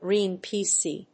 意味・対訳 グリーン‐ピーシー